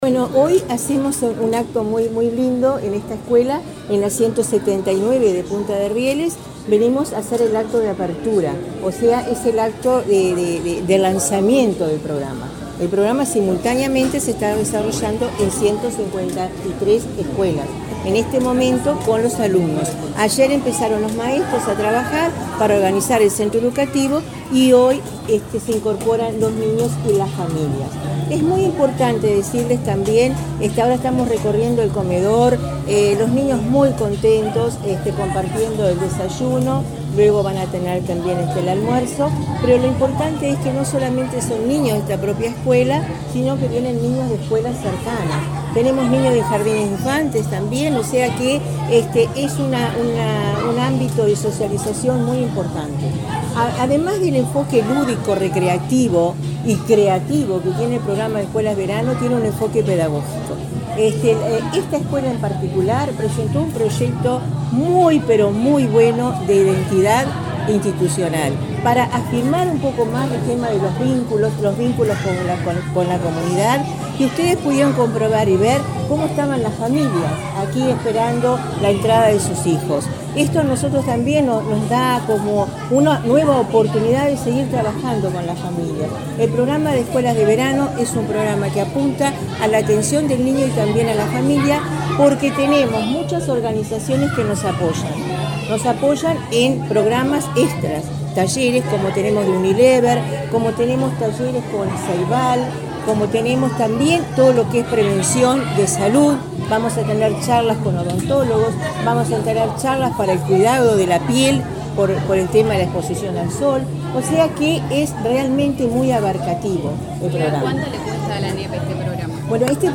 Declaraciones de la directora de Educación Inicial y Primaria, Olga de las Heras
La directora de Educación Inicial y Primaria, Olga de las Heras, dialogó con la prensa, durante el lanzamiento del programa Escuelas de Verano 2024,